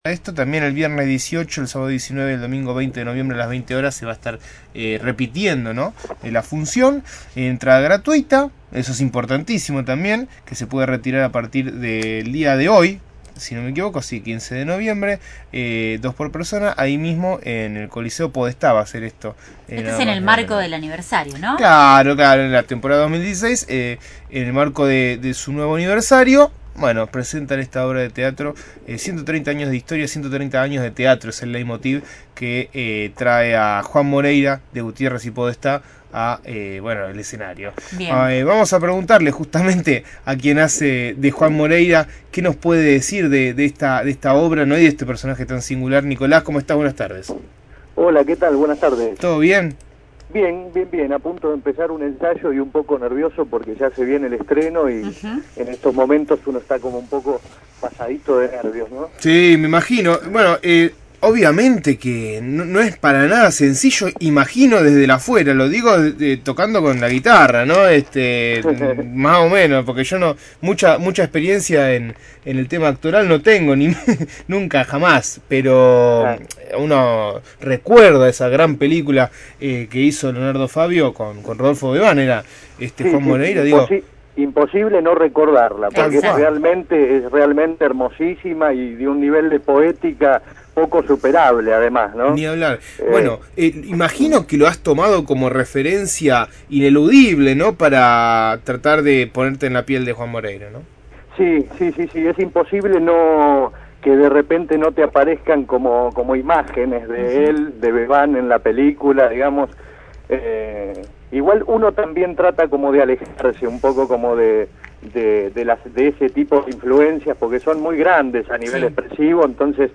dialogó con el equipo de «Dame una señal» sobre el estreno de la pieza teatral en el marco del 130° aniversario del Teatro Municipal Coliseo Podestá.